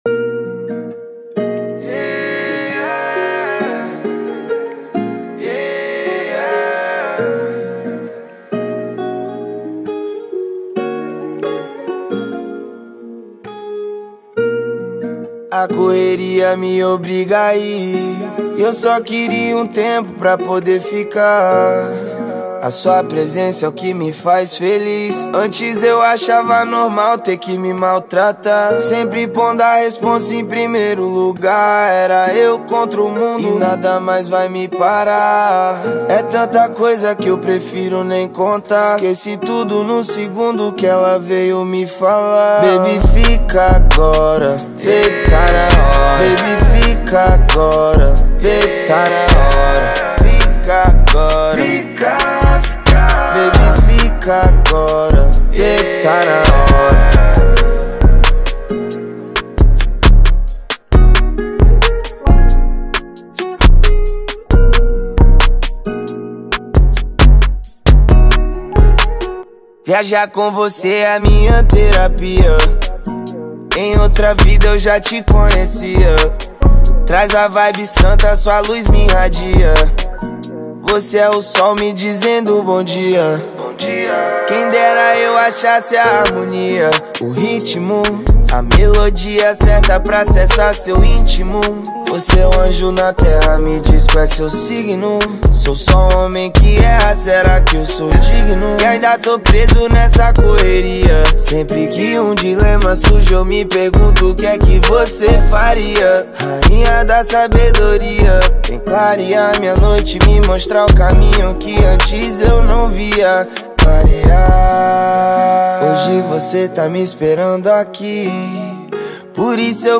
2025-01-05 14:08:45 Gênero: Trap Views